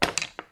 crash.mp3